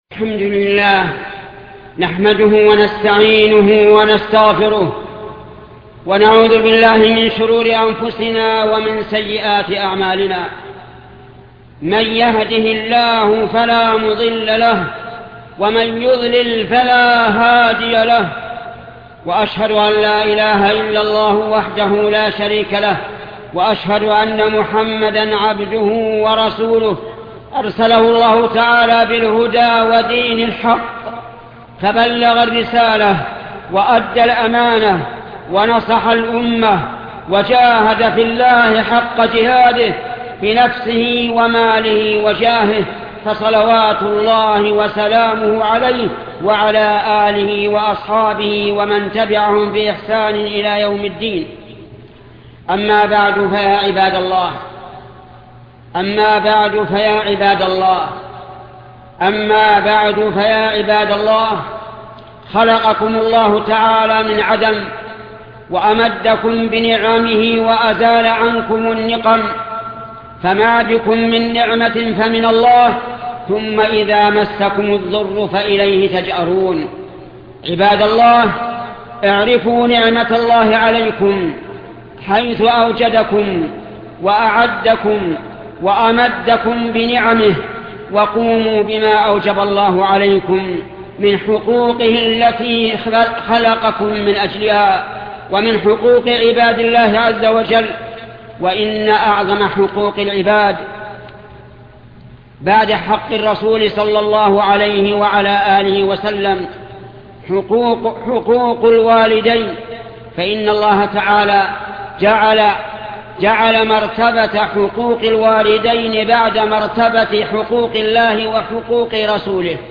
خطبة بر الوالدين الشيخ محمد بن صالح العثيمين